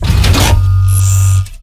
droidic sounds
attack1.wav